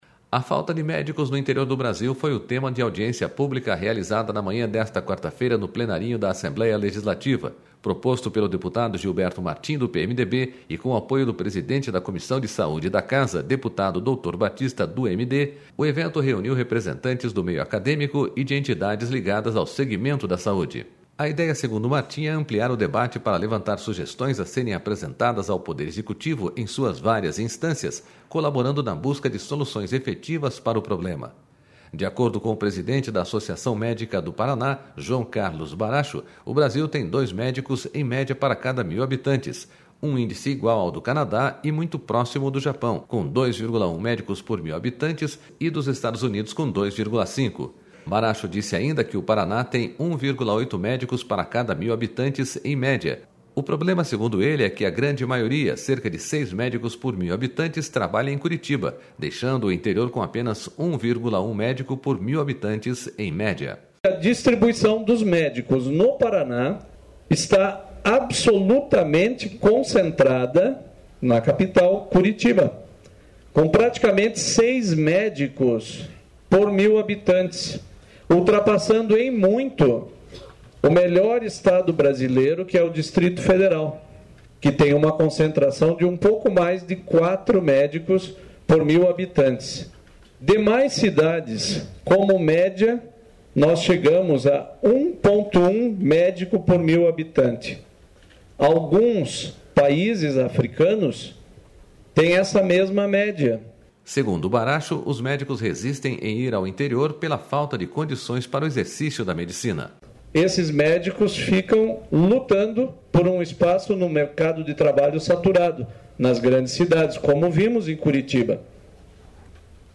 Audiência Pública debate falta de médicos no interior
A falta de médicos no interior do Brasil foi o tema da audiência pública realizada na manhã desta quarta-feira no plenarinho da Assembleia Legislativa.// Proposto pelo deputado Gilberto Martin, do PMDB, e com apoio do presidente da Comissão de Saúde da Casa, deputado Dr. Batista, do MD, o evento reu...